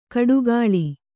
♪ kaḍugāḷi